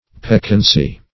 Search Result for " peccancy" : The Collaborative International Dictionary of English v.0.48: Peccancy \Pec"can*cy\, n. [L. peccantia.] 1.
peccancy.mp3